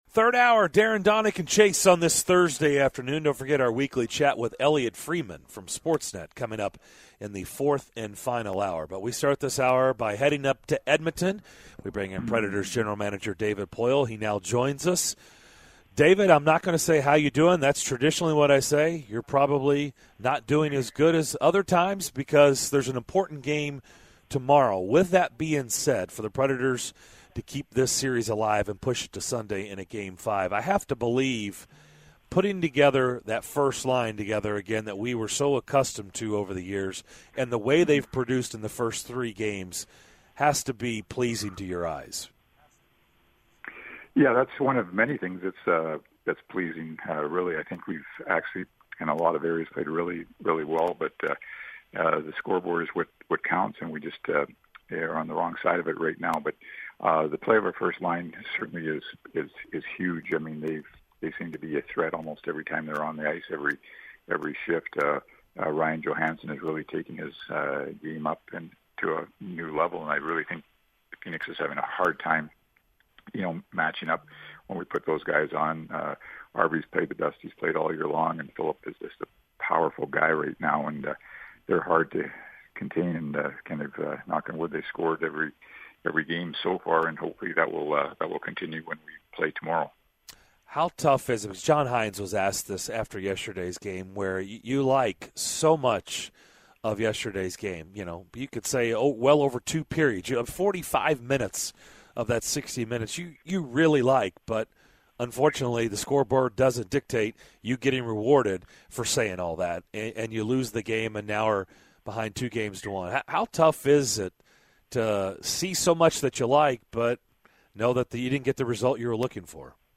In the third hour of Thursday's DDC: the guys talk with Preds GM David Poile about Wednesday's loss and take calls from Preds fans about what they would do to the Preds lineup if they were John Hynes.